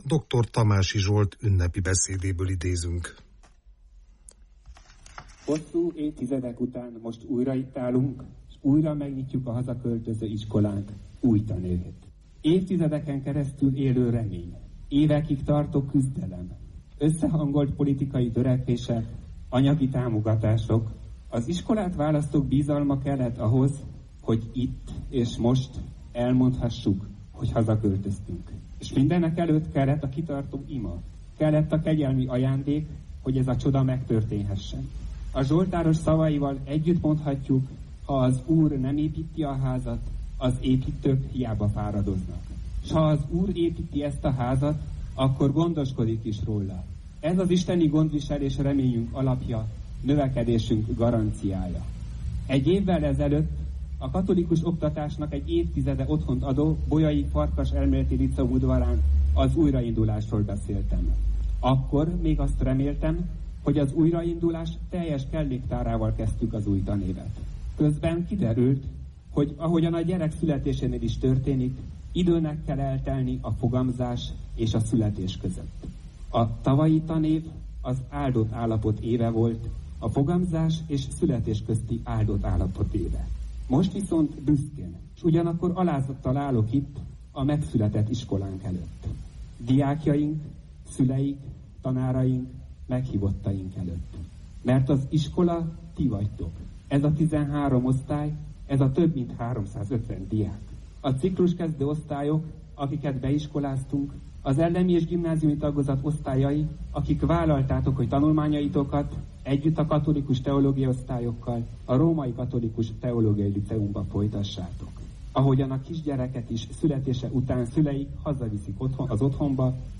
A tanévnyitó ünnepségen elhangzott ünnepi beszédekből és interjúkból készült összeállításunkat hallgathatják meg.